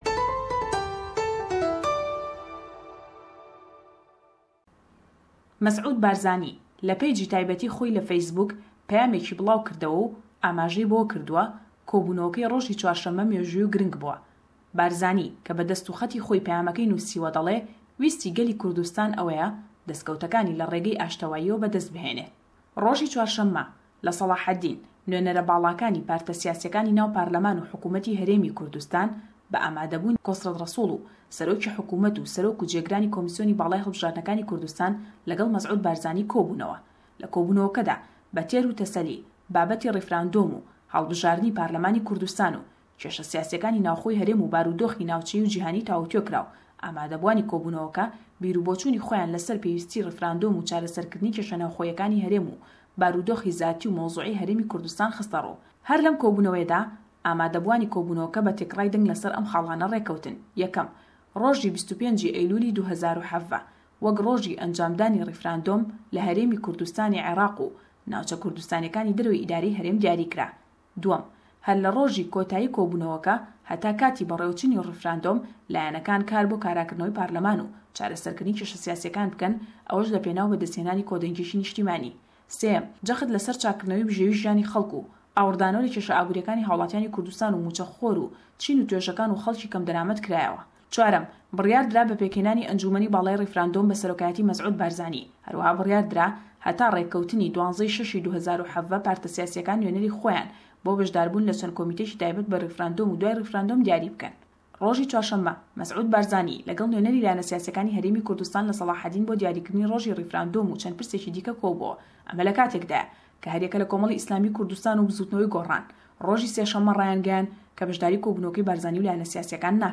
Le em raportey peyamnêrman